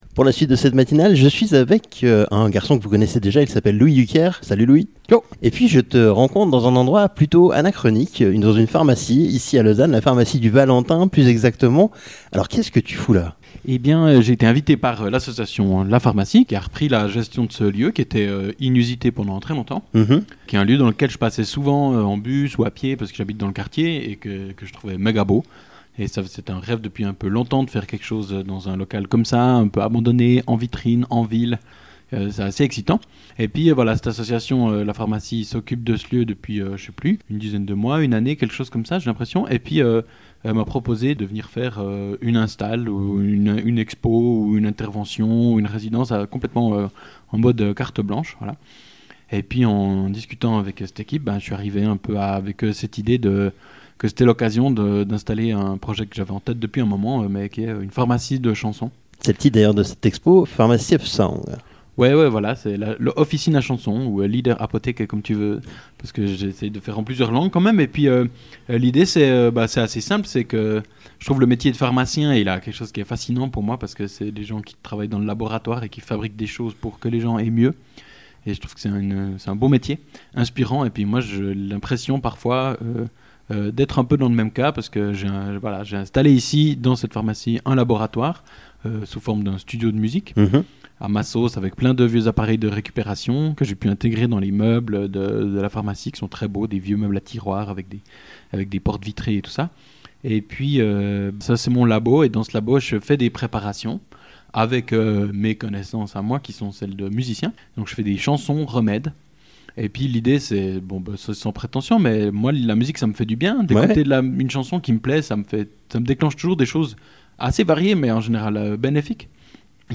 Après l’interview